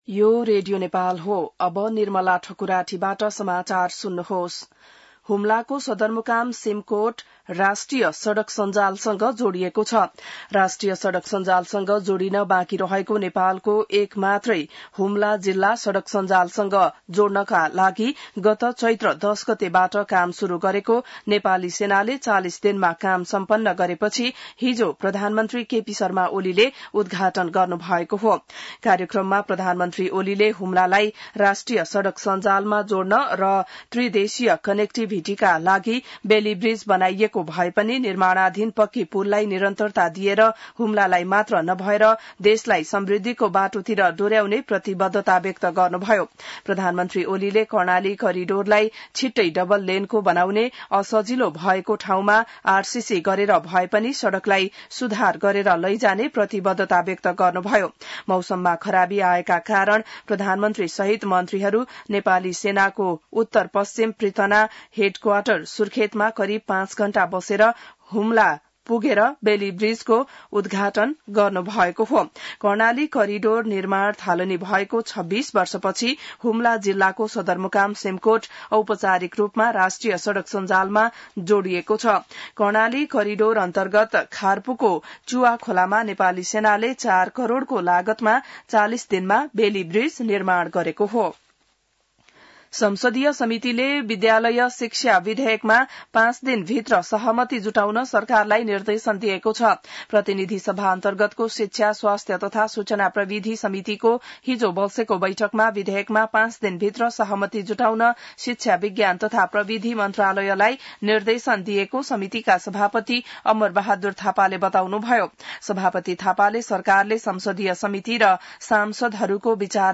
बिहान ६ बजेको नेपाली समाचार : २३ असार , २०८२